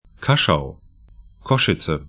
Kaschau 'kaʃau Košice 'kɔʃɪtsə sk Stadt / town 48°43'N, 21°15'E